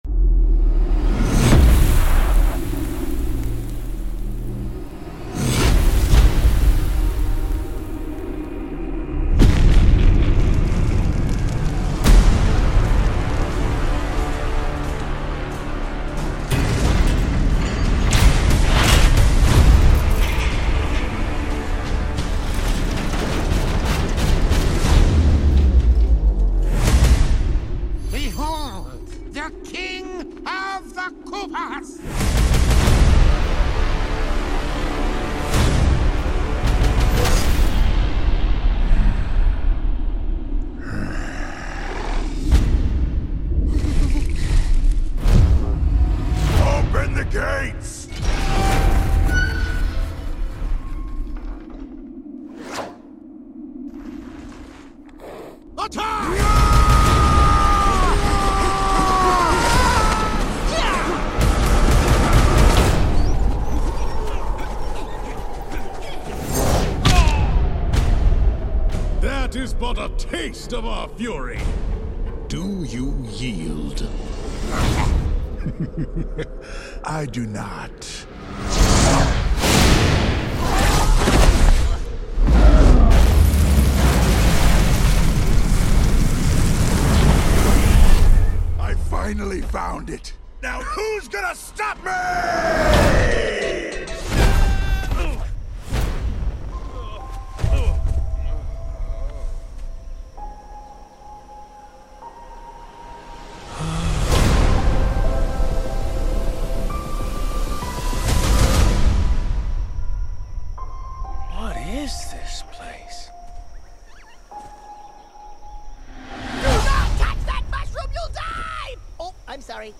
The Super Mario Bros. Movie sound effects free download
Movie Mp3 Sound Effect The Super Mario Bros. Movie | Sound Design Only in theatres April 7, 2023 Honest opinion?